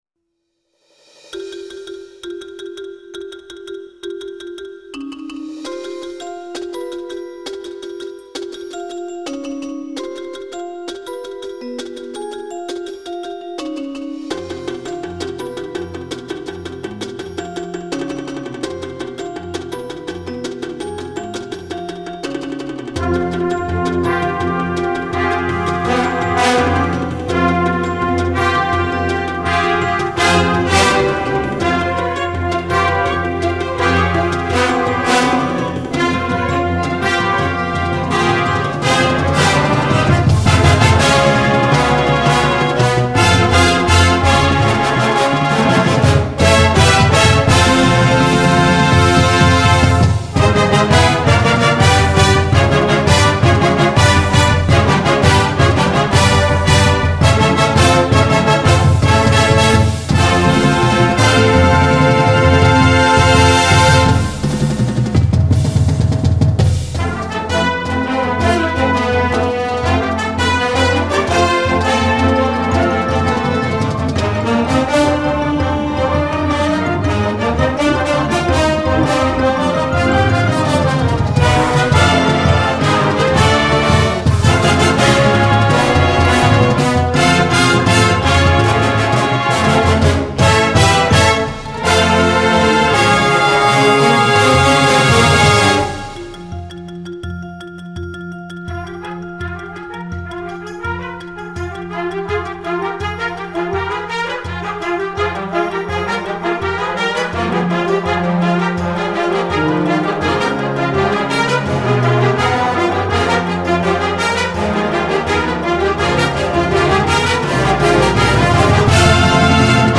classical themed Marching Band Show scored for smaller bands